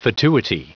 Prononciation du mot fatuity en anglais (fichier audio)
Prononciation du mot : fatuity